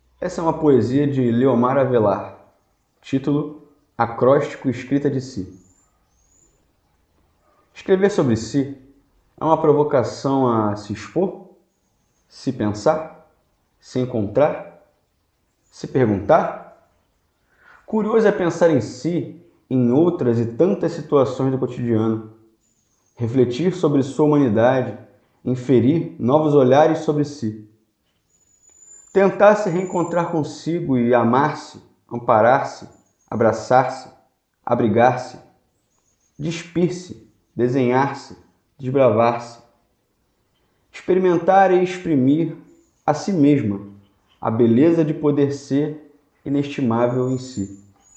Técnica: poesia
Poesia com voz humana